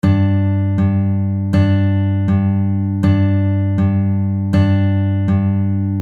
Exercise 2 - Steady bass With pinch
In exercise 2, we have a steady bass. We play the bass note at each beat of the bar 1-2-3-4.
Here we play the B string at the 3rd fret (using the pinky of the left hand!) on beats 1 and 3.